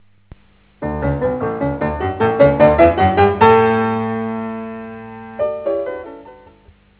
中期のソナタではほとんど全ての主題は上昇するデザインを持っている。